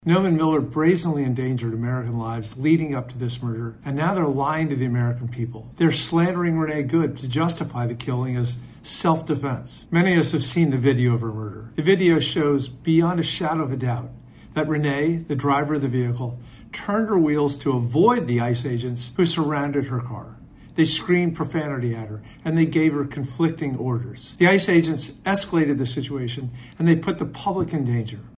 Northern Virginia Democratic Congressman Don Beyer says Secretary Noem and Trump aide Steven Miller should resign from their posts following the shooting: